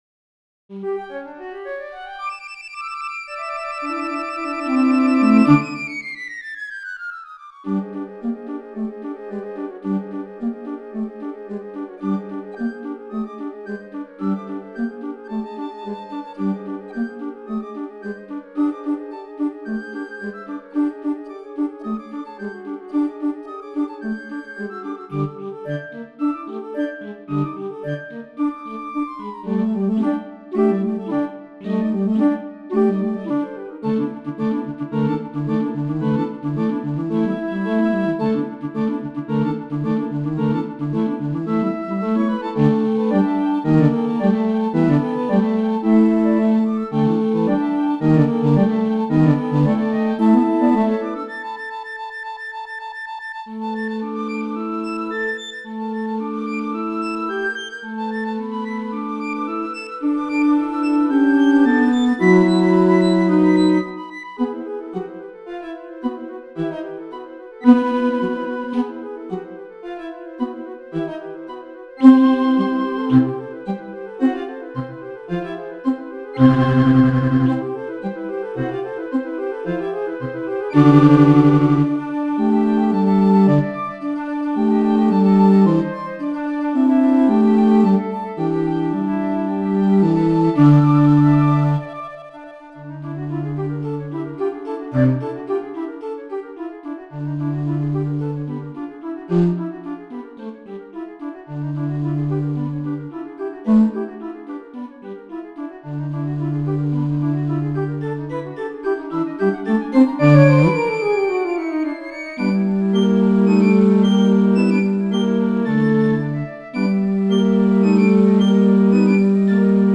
Piccolo, 2 Flutes, Alto Flute